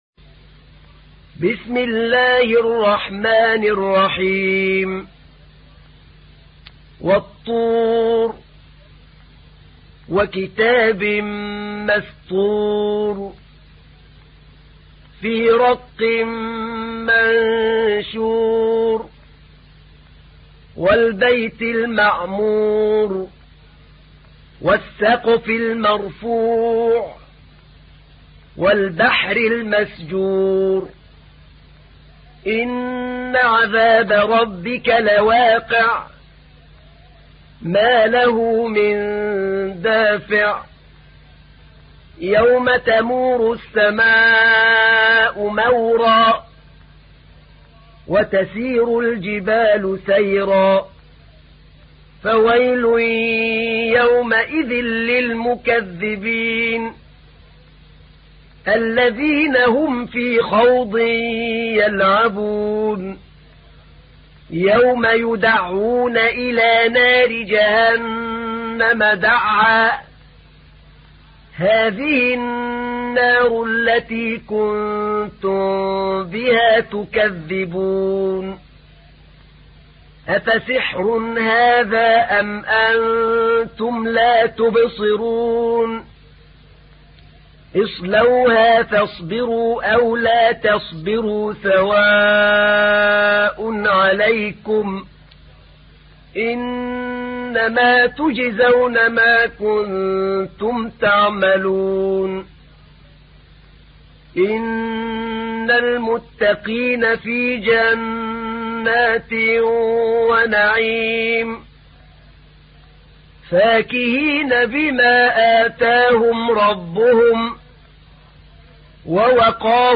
تحميل : 52. سورة الطور / القارئ أحمد نعينع / القرآن الكريم / موقع يا حسين